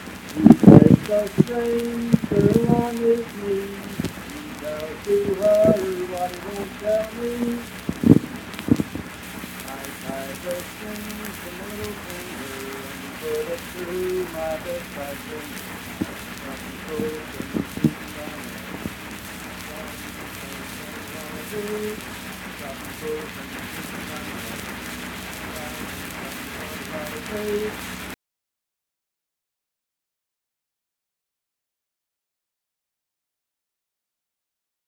Unaccompanied vocal music performance
Voice (sung)
Randolph County (W. Va.)